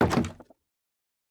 Minecraft Version Minecraft Version 1.21.5 Latest Release | Latest Snapshot 1.21.5 / assets / minecraft / sounds / block / bamboo_wood_door / toggle2.ogg Compare With Compare With Latest Release | Latest Snapshot